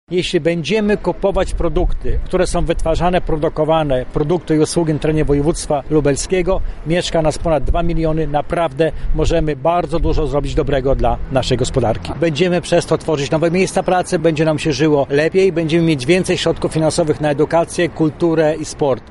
Na konferencji zorganizowanej przez Urząd Marszałkowski szkolili się w kwestii zarządzania, czy budowania własnej marki. Wicemarszałek Krzysztof Grabczuk podkreślał jak ważne jest świadomość na temat regionalnych produktów: